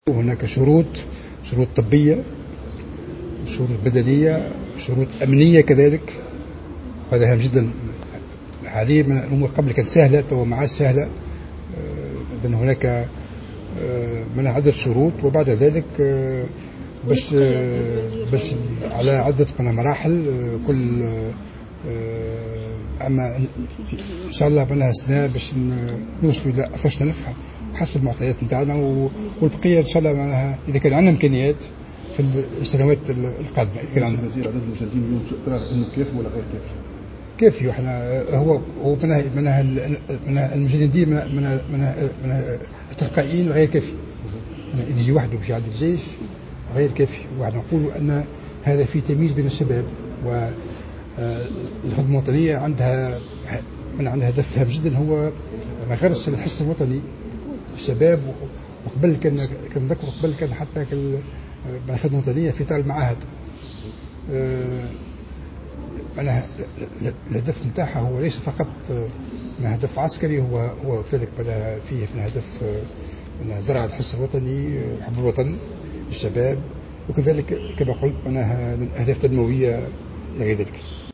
أكد وزير الدفاع الوطني فرحات الحرشاني، في تصريح للجوهرة أف أم لدى اشرافه اليوم الخميس، على إفتتاح أشغال الملتقى الوطني حول الخدمة الوطنية انه من الضروري مراجعة الإطار القانوني للخدمة العسكرية بما يستجيب للدستور الجديد والواقع الامني والاجتماعي والاقتصادي للبلاد.